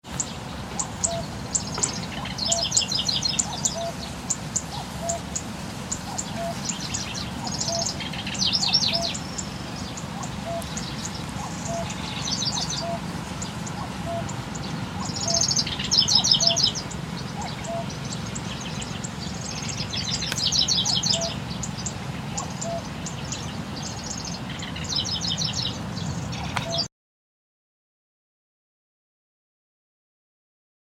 Frustrating habitat at the Wenyu River paddies.
On arriving home, the first thing I did was download the sound file from the video camera and check out Xeno Canto.
Locustella Warbler
Styan’s songs are typically not variable – and sound rather less varied but rather sweeter than the locustella in your recording.
locustella-warbler.mp3